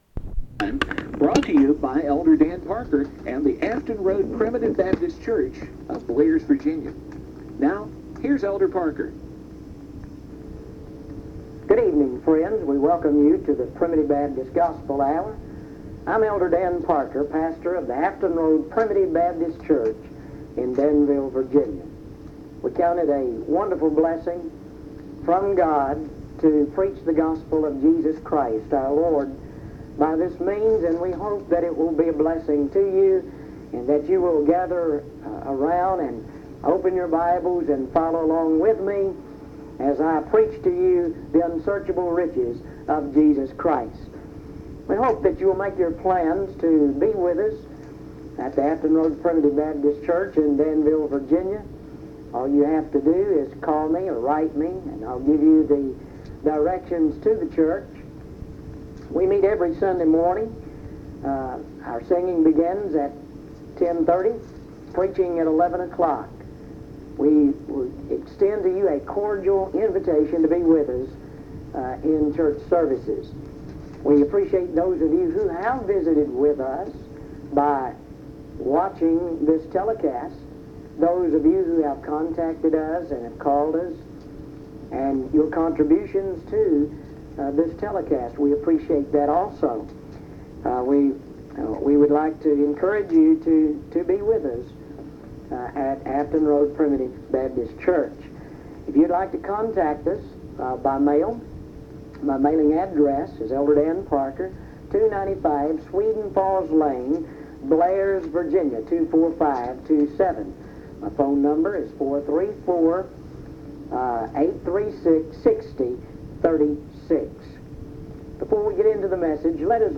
1 Corinthians 15:24-28; Recording from a broadcast